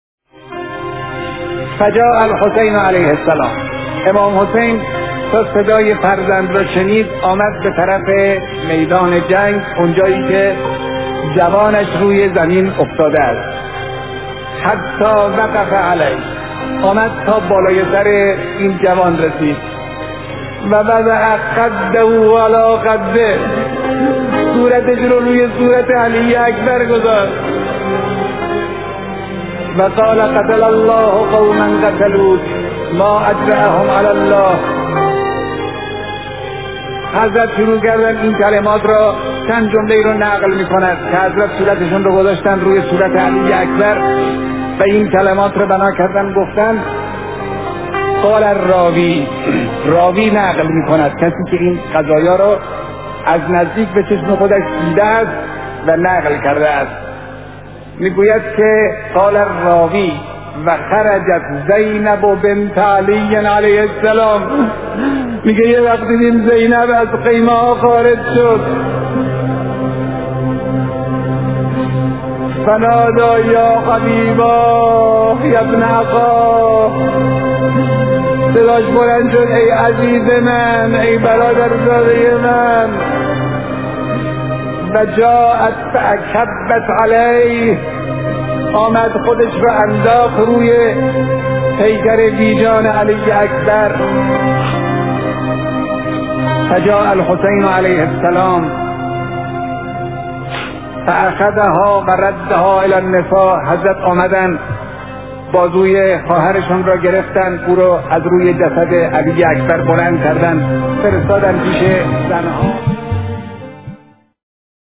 روضه علی اکبر
روضه-علی-اکبر.mp3